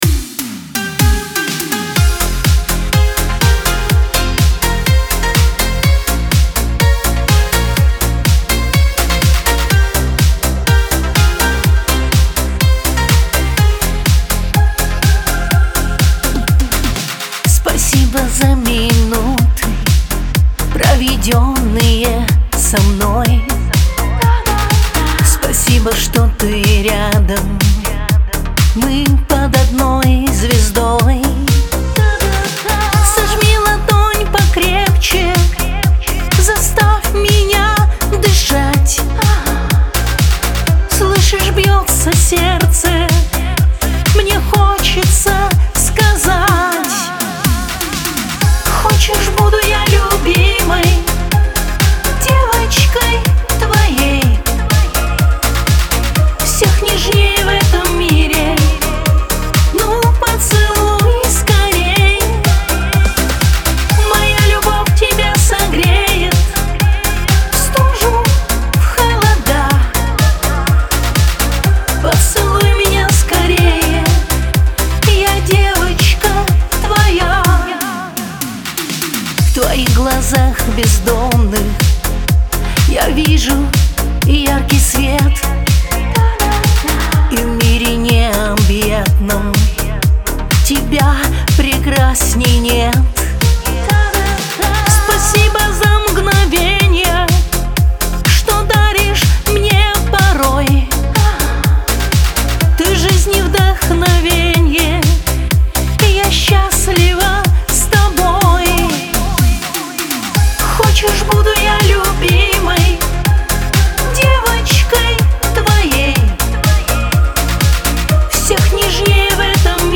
диско , эстрада
dance
pop